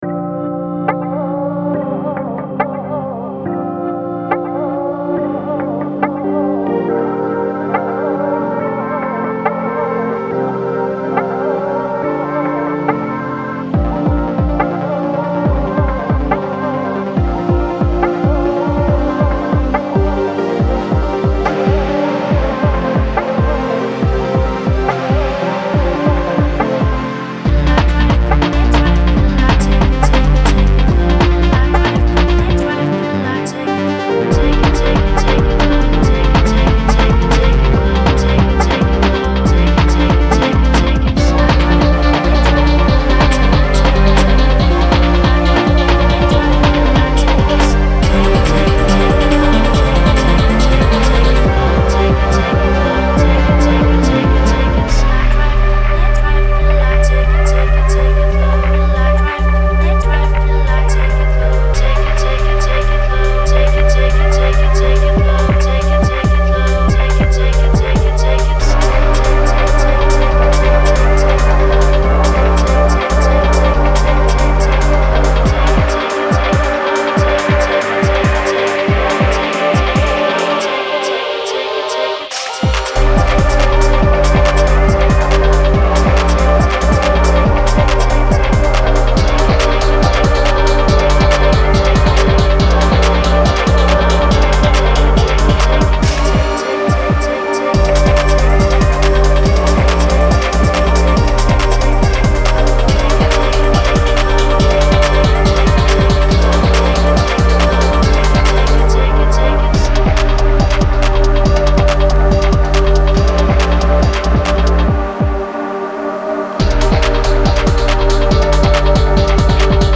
Lovely moody bit of broken beat house